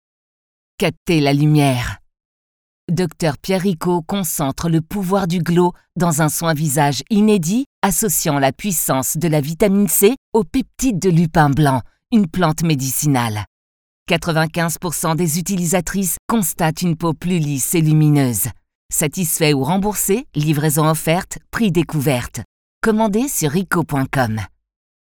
Voix off